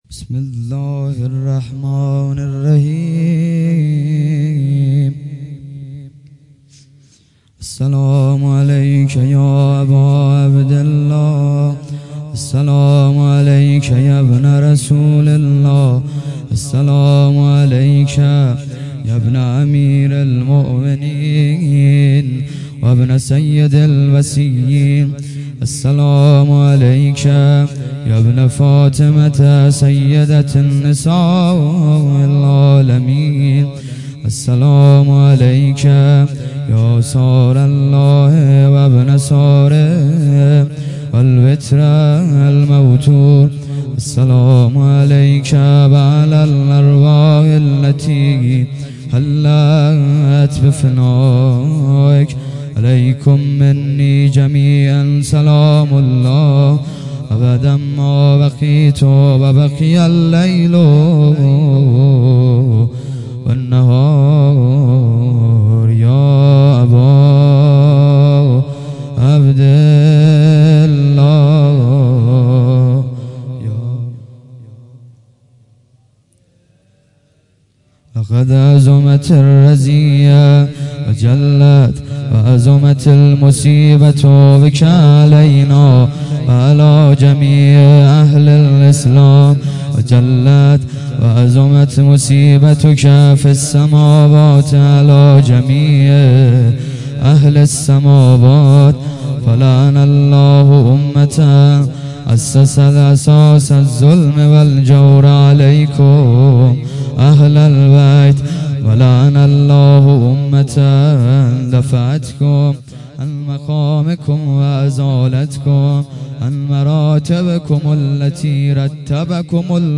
قرائت زیارت عاشورا